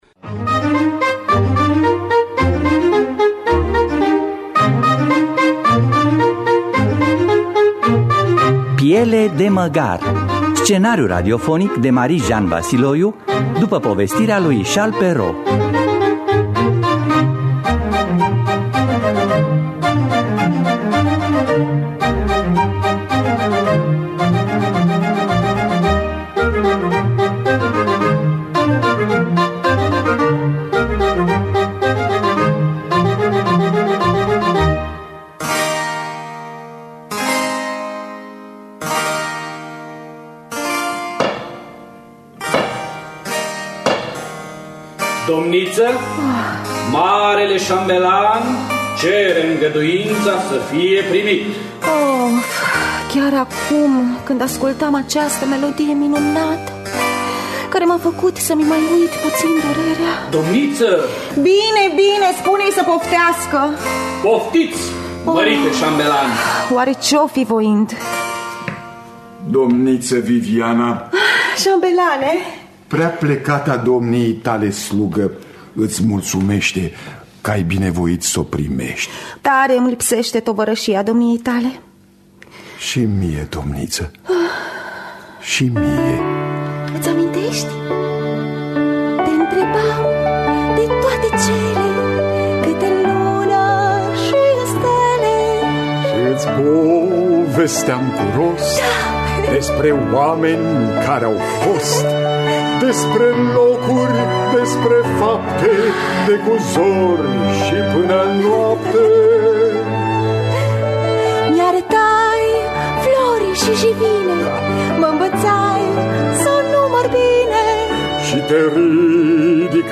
Piele de măgar de Charles Perrault – Teatru Radiofonic Online